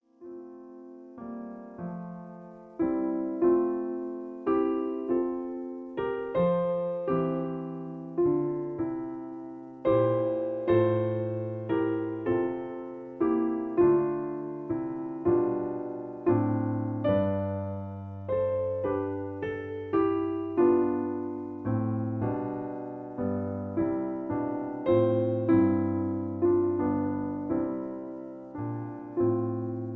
In F. Piano Accompaniment